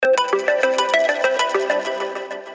• Качество: 320, Stereo
без слов
короткие
звонкие